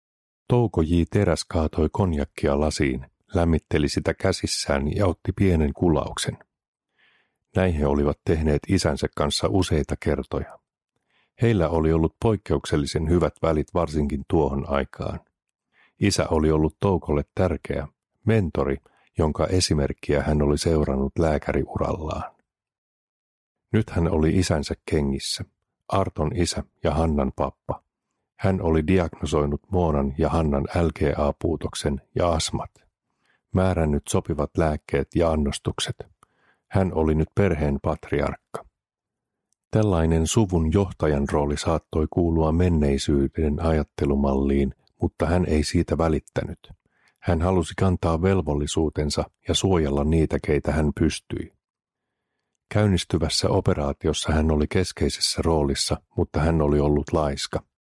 KRATTI – Ljudbok – Laddas ner